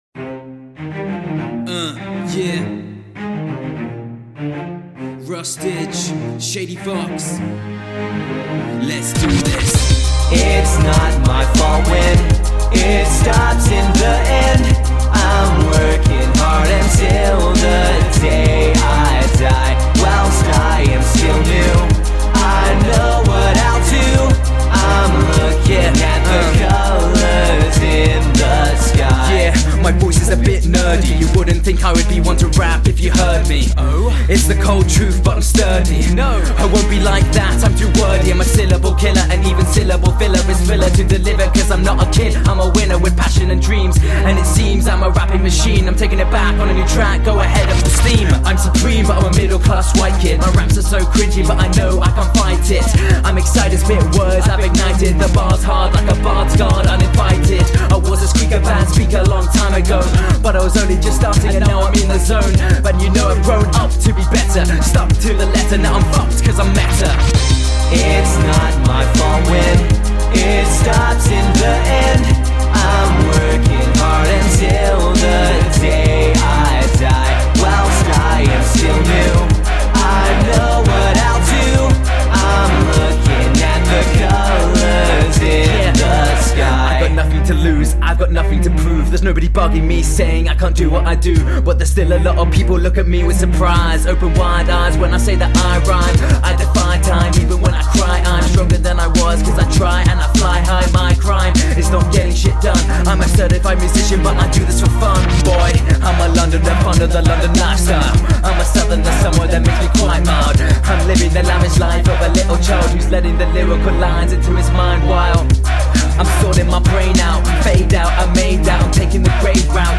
chorus vocals